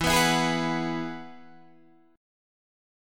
Eadd9 chord {x 7 6 4 7 7} chord